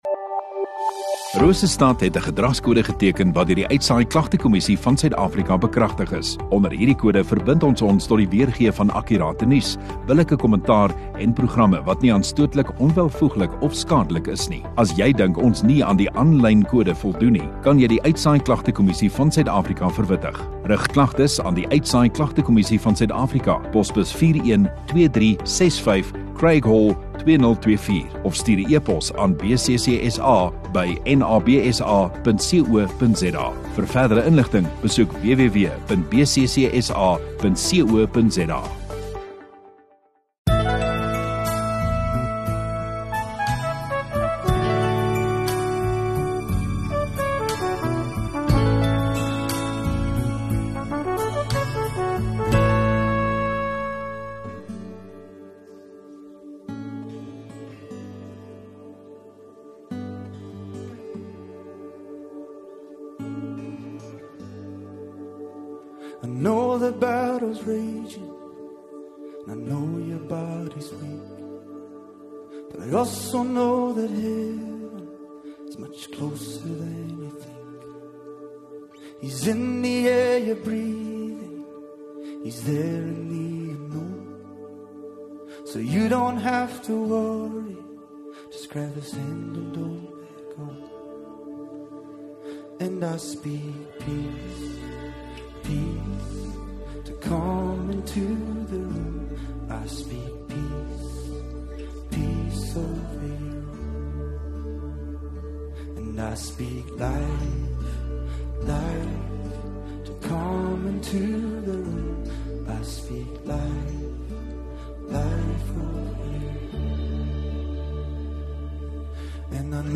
26 Dec Donderdag Oggenddiens